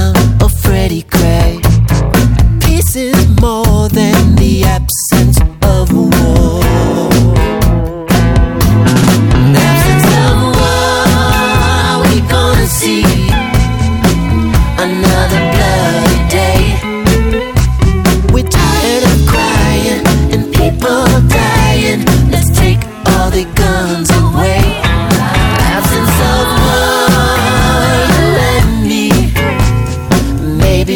0 => "Rhythm'n'blues, soul"